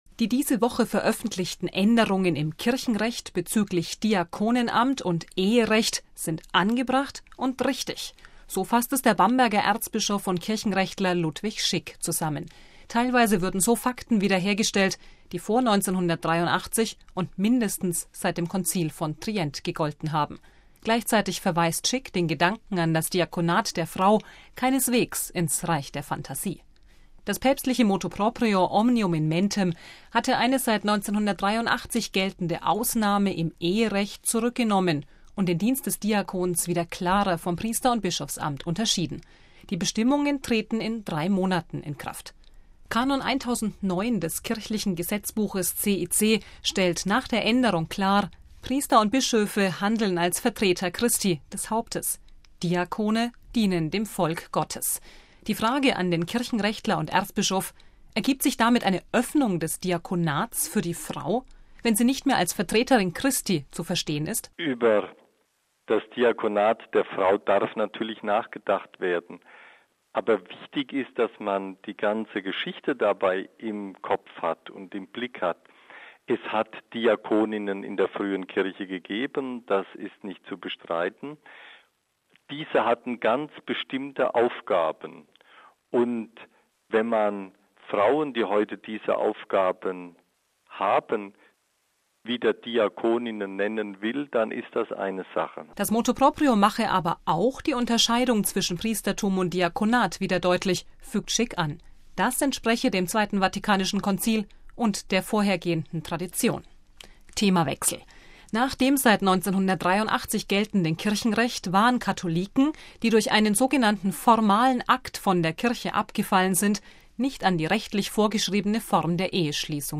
MP3 Die diese Woche veröffentlichten Änderungen im Kirchenrecht bezüglich Diakonen-Amt und Eherecht sind angebracht und richtig. So fasst es der Bamberger Erzbischof und Kirchenrechtler Ludwig Schick zusammen.